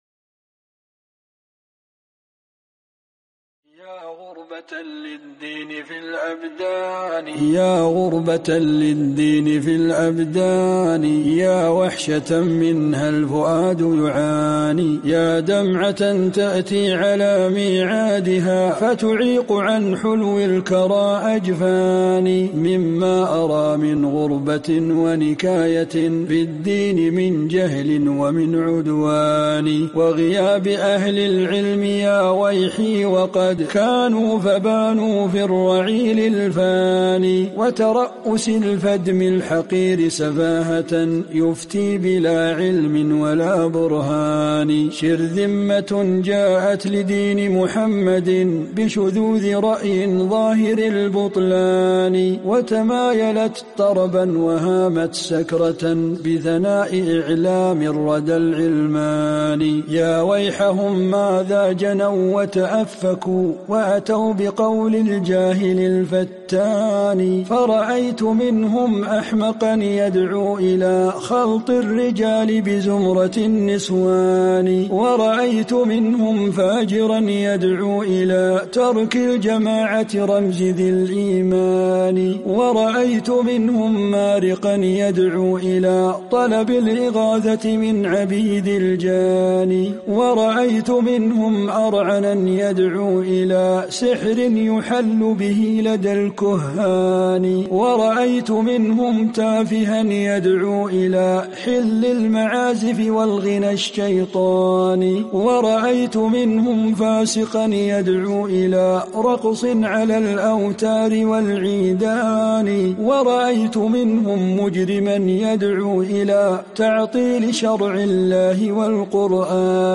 غربة الدين : شعر الشيخ